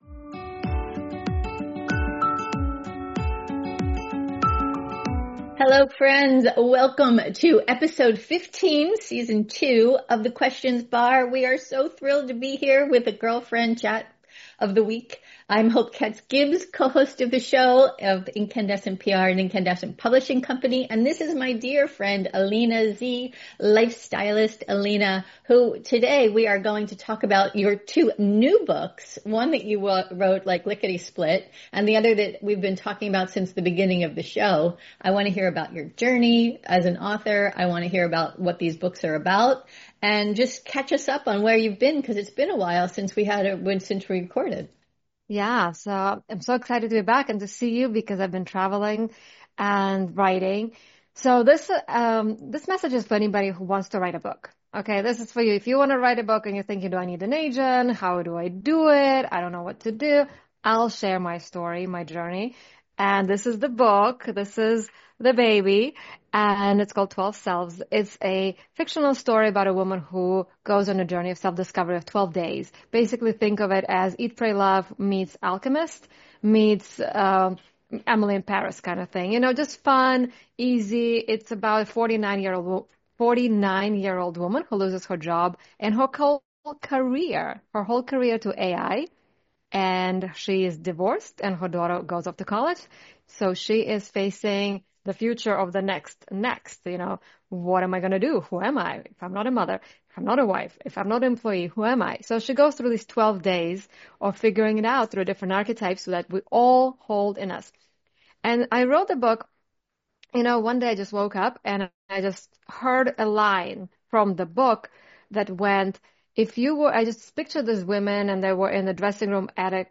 which invites you in to listen in on a chat with two girlfriends talking about topics of the day that touch their minds, bodies, spirits, souls, and hearts